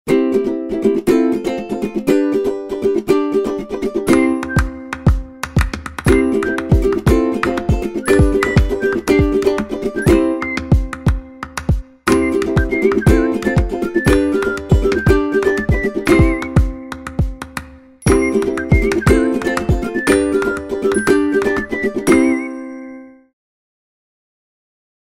укулеле , свист